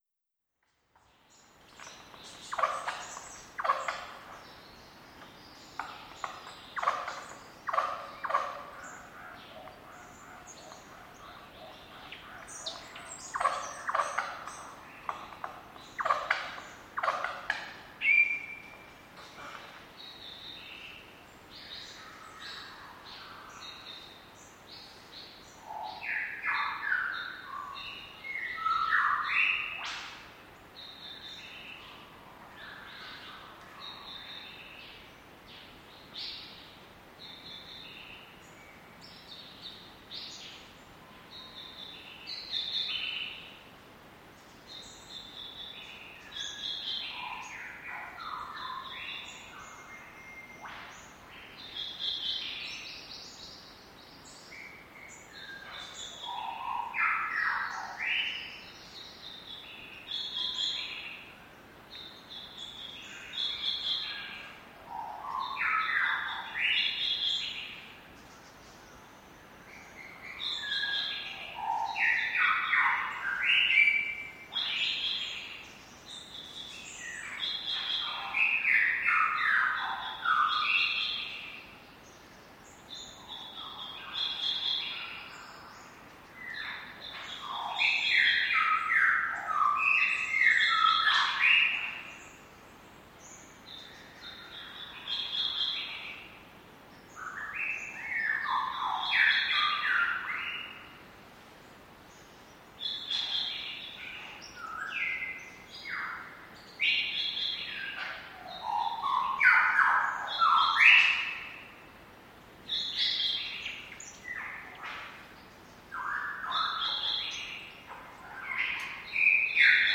• lyrebirds squeaking.wav
Lyrebirds_Squaking_96e.wav